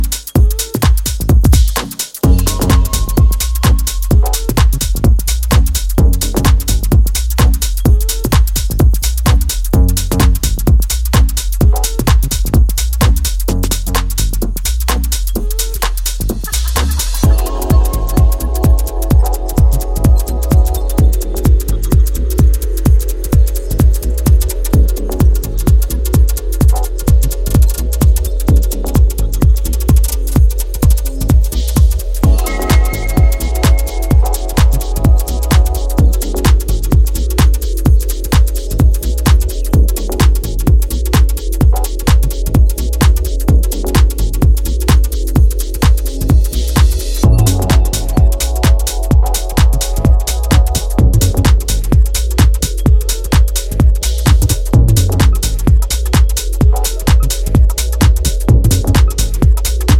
weighty remix